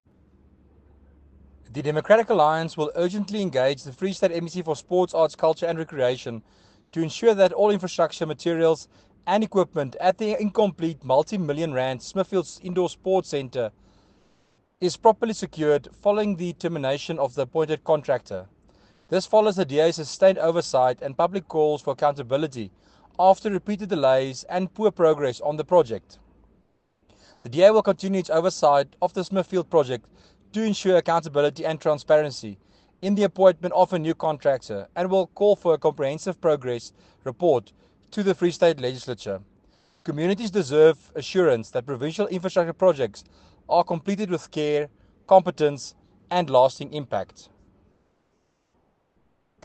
English and Afrikaans soundbites by Werner Pretorius MPL and